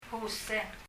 パラオ語 PALAUAN language study notes « calculation 計算 water 水 » balloon 風船 huseng [hu:sɛŋ] 日本語のまま 英） balloon 日） 風船 Leave a Reply 返信をキャンセルする。